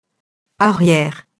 arrière [arjɛr]